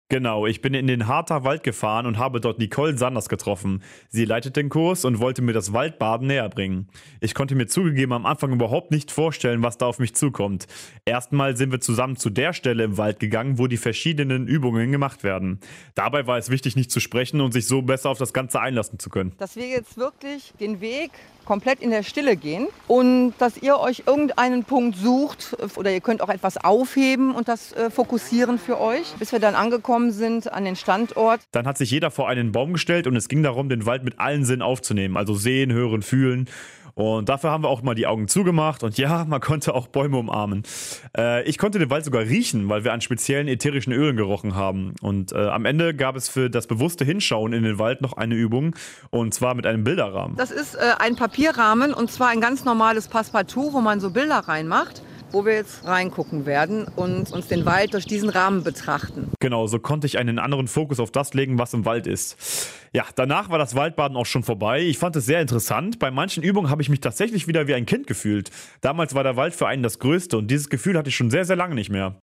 RADIO 90,1 | Beitrag zum Anhören